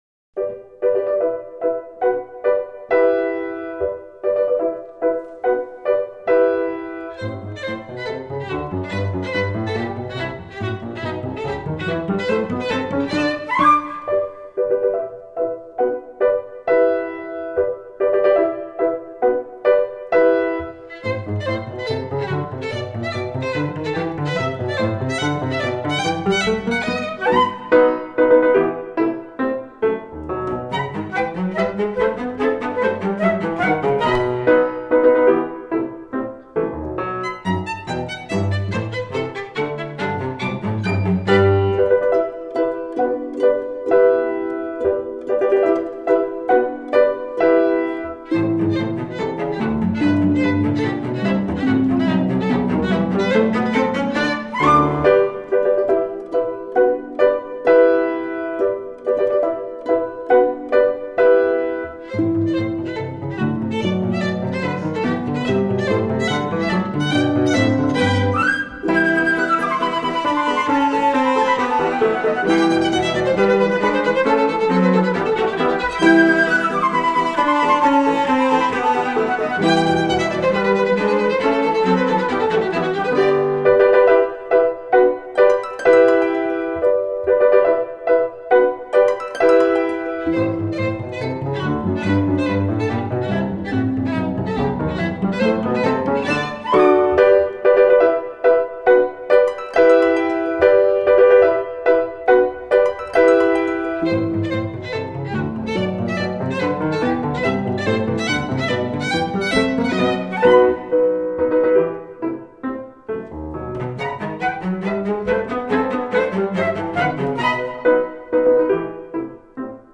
Holiday Music/Classical Collections